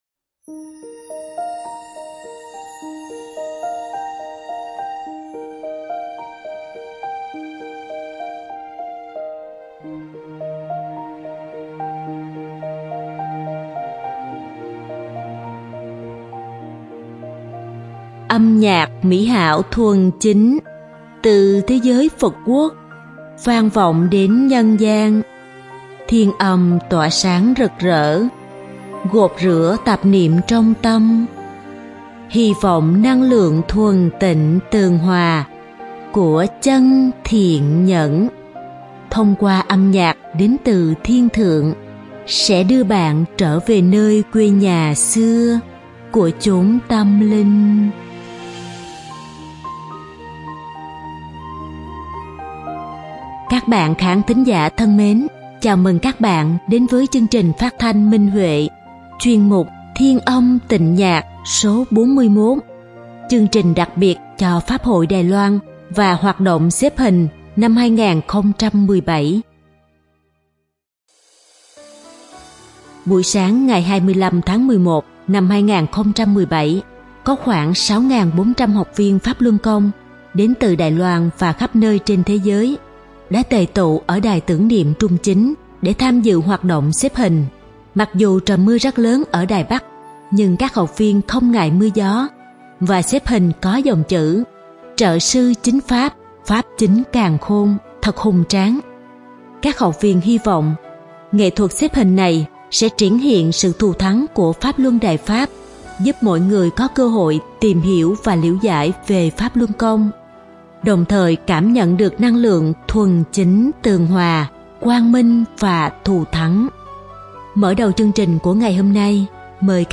Các bạn khán thính giả thân mến, chào mừng các bạn đến với chương trình phát thanh Minh Huệ, chuyên mục “Thiên Âm Tịnh Nhạc” Số 41: Chương trình đặc biệt cho Pháp hội Đài Loan và hoạt động xếp hình.